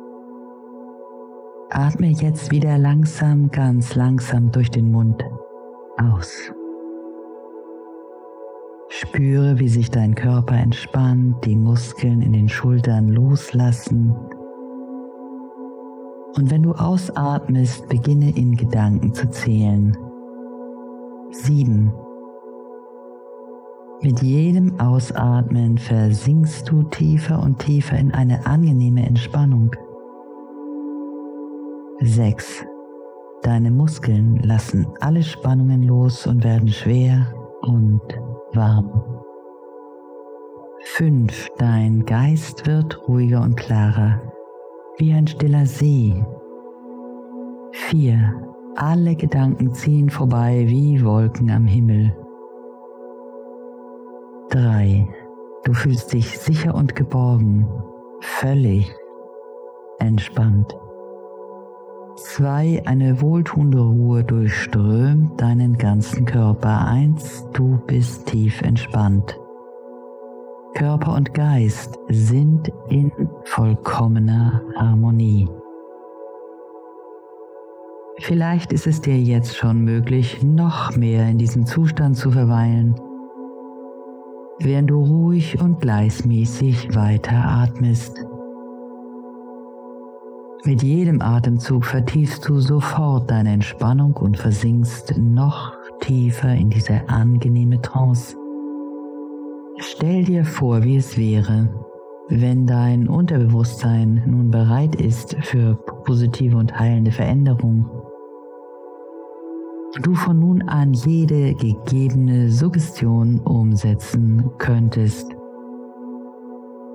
• Musik: Ja;